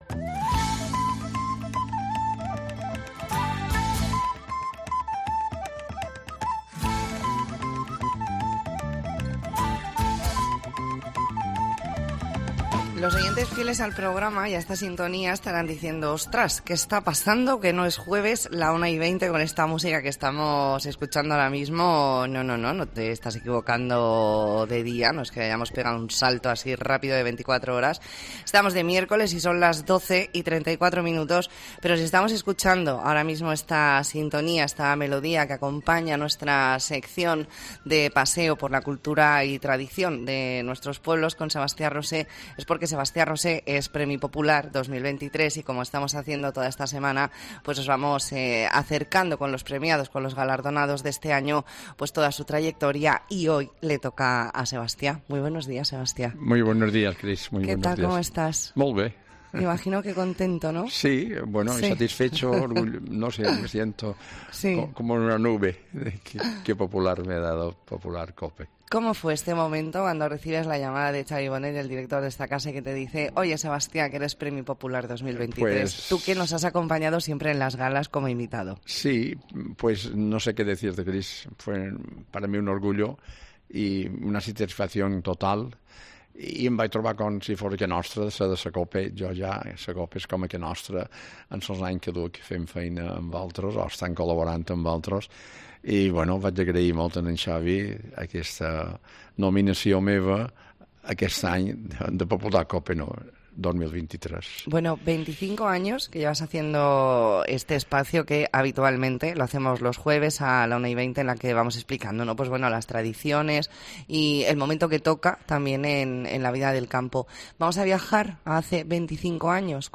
Entrevista en La Mañana en COPE Más Mallorca, miércoles 22 de noviembre de 2023.